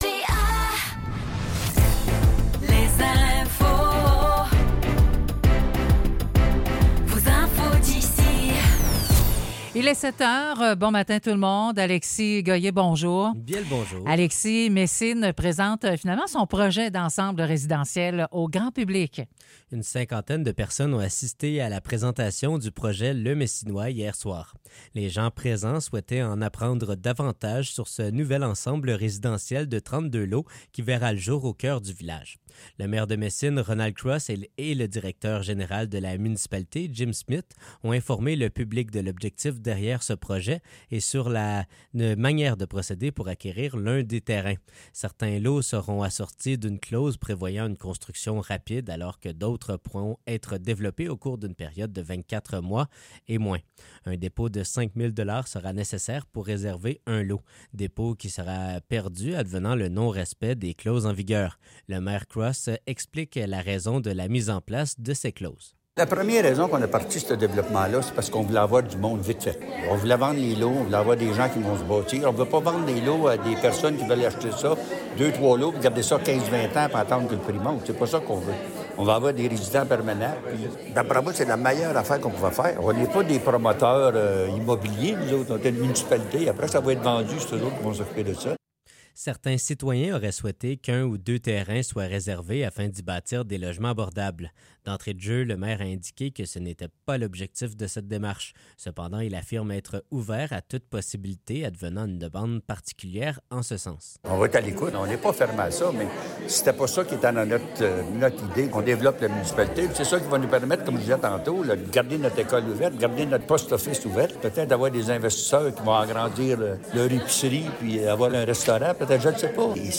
Nouvelles locales - 18 juillet 2024 - 7 h